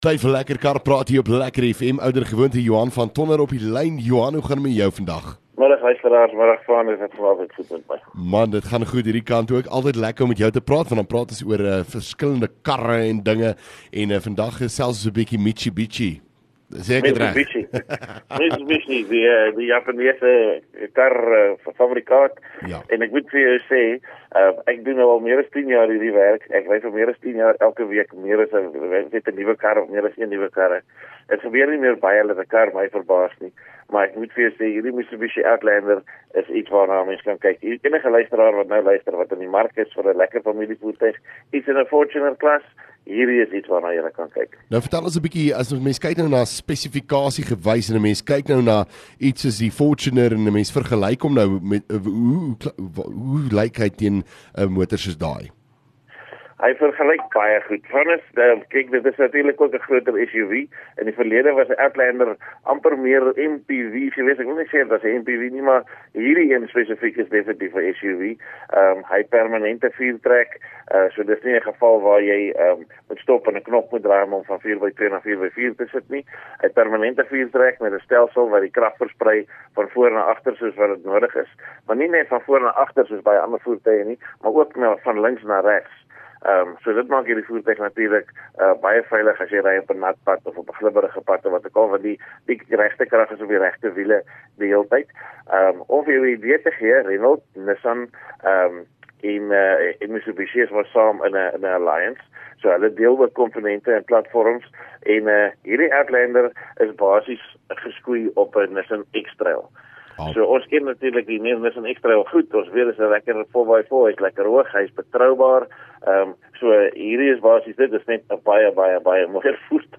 LEKKER FM | Onderhoude 4 Aug Lekker Kar Praat